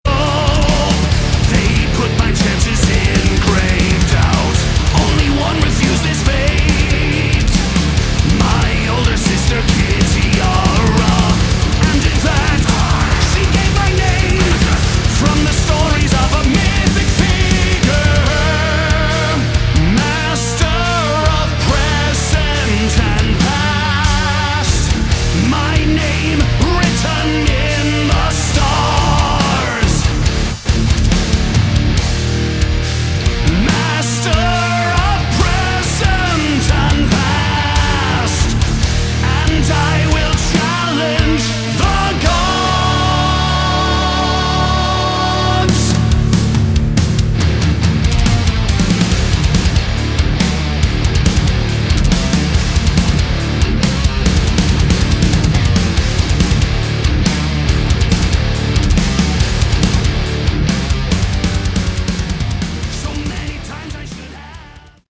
Category: Rock
bass guitar, backing vocals
drums, backing vocals
guitar, backing vocals
lead vocals, backing vocals